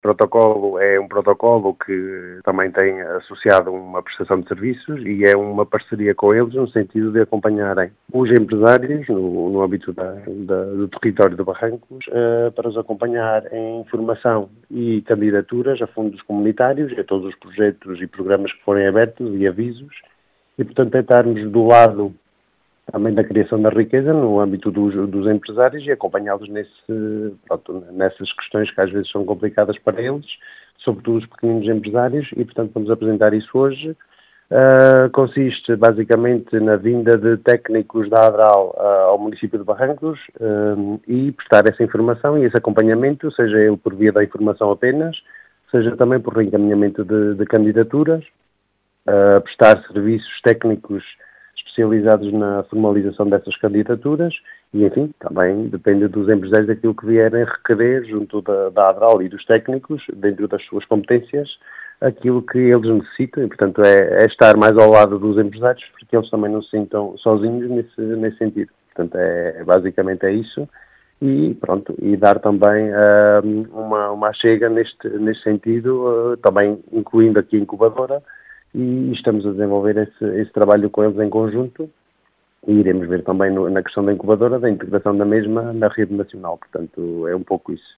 As explicações são de Leonel Rodrigues, presidente da Câmara Municipal de Barrancos.
Leonel-Rodrigues.mp3